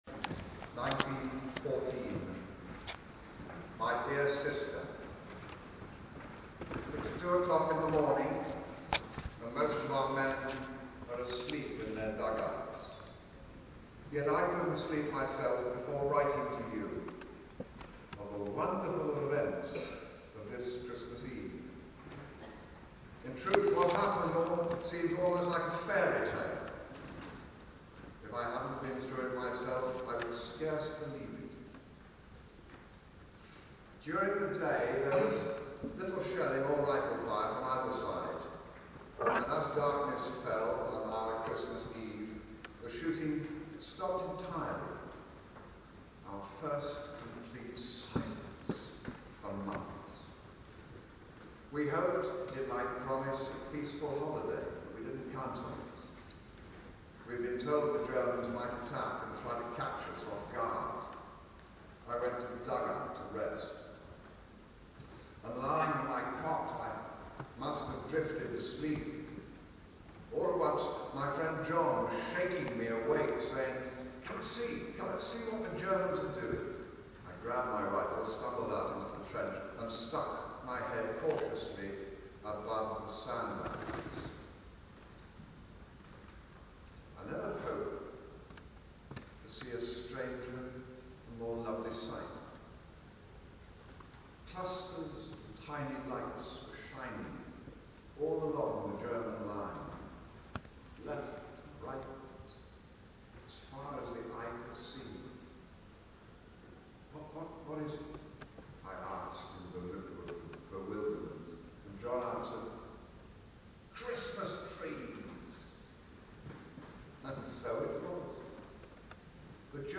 Jeremy Irons at Christmas Carol Concert at St. Paul’s Covent Garden
Jeremy Irons was one of the readers at a Christmas carol concert at St. Paul’s (The Actor’s Church) in Covent Garden, London on Saturday 7 December 2013.
Jeremy read from “Christmas in the Trenches” by Aaron Shepherd, a fictional version of the story of the legendary Christmas Truce of 1914.